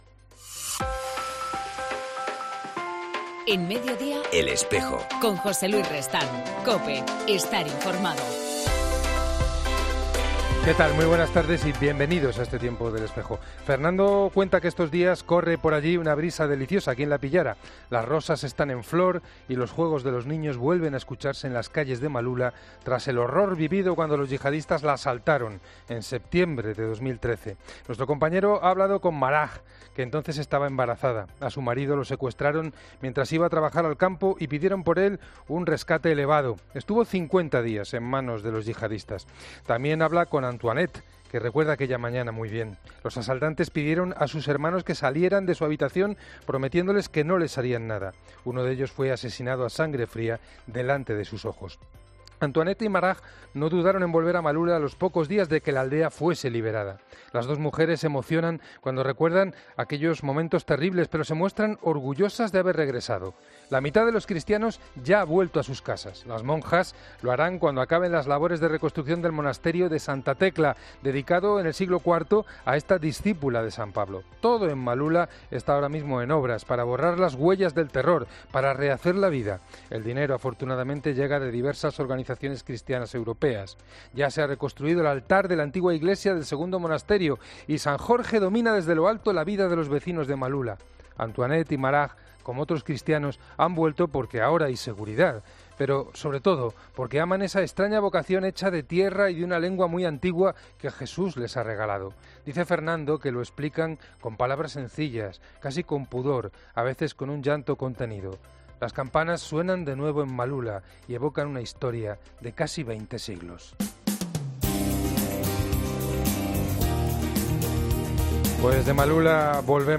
En El Espejo del 15 de junio hablamos con el obispo de la Diócesis de Coria-Cáceres, Mons. Francisco Cerro